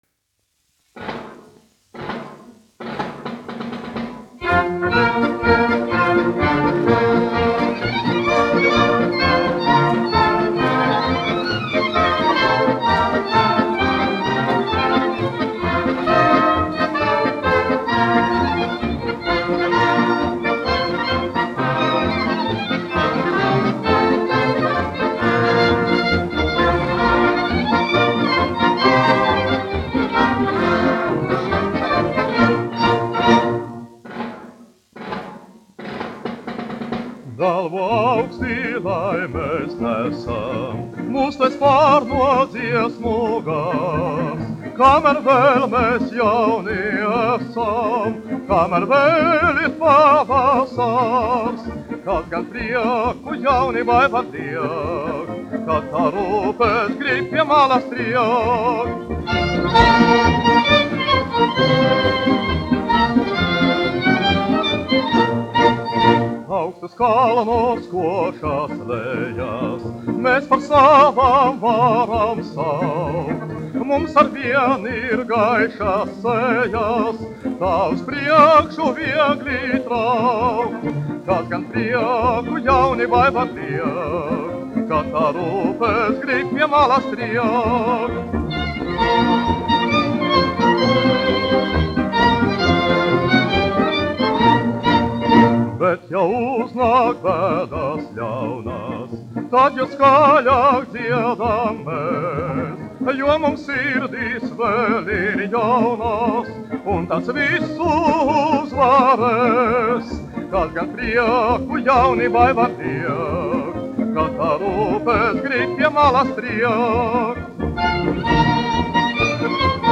1 skpl. : analogs, 78 apgr/min, mono ; 25 cm
Populārā mūzika
Marši
Skaņuplate
Latvijas vēsturiskie šellaka skaņuplašu ieraksti (Kolekcija)